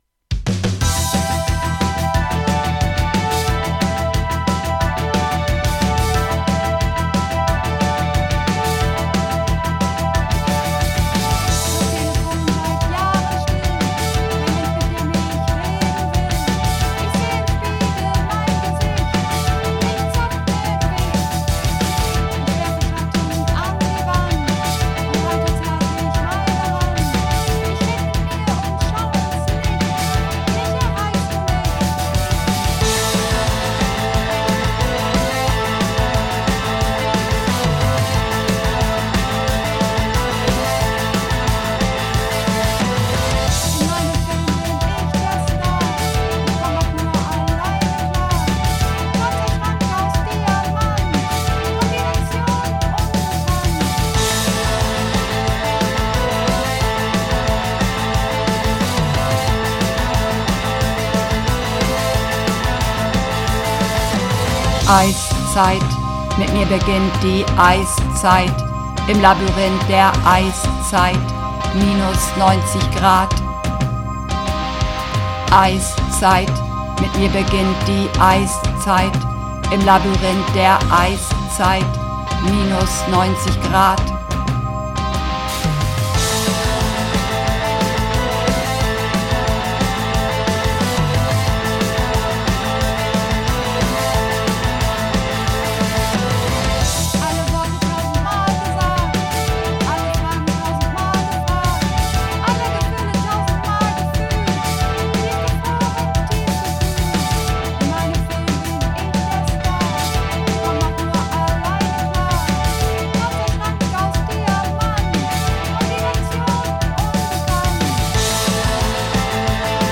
Übungsaufnahmen - Eiszeit
Eiszeit (Männer)
Eiszeit__3_Maenner.mp3